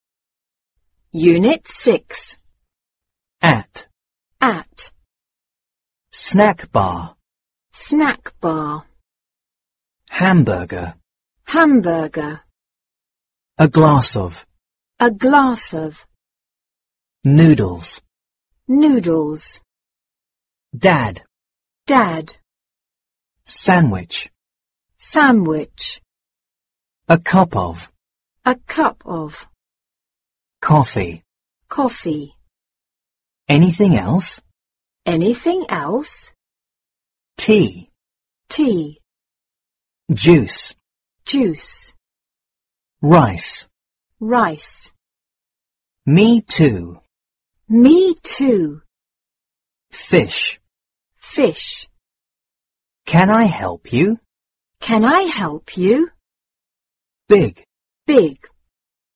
四年级英语上Unit 6 单词.mp3